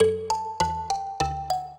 mbira
minuet13-11.wav